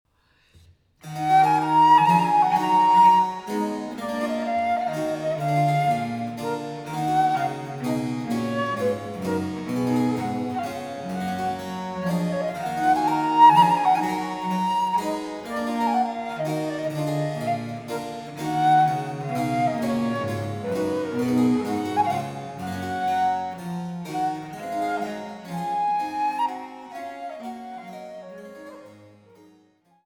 Traversflöte
Cembalo
Menuet